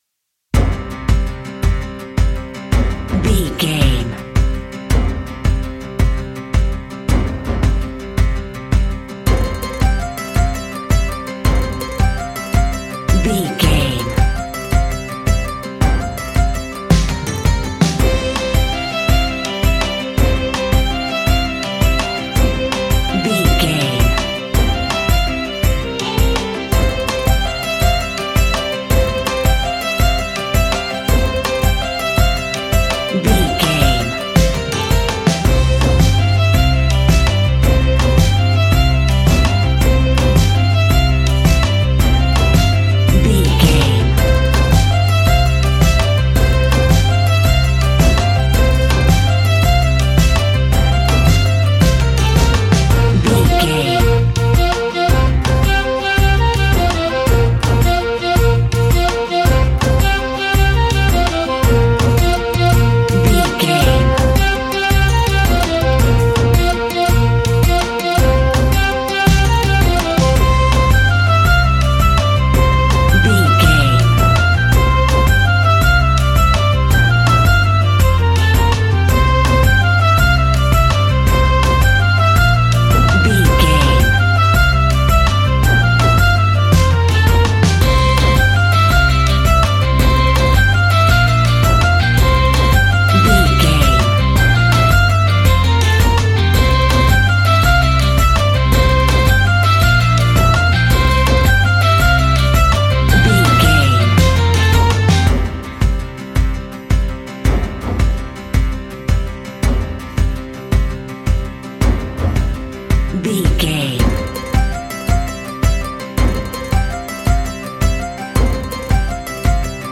Mixolydian
acoustic guitar
mandolin
ukulele
lapsteel
drums
double bass
accordion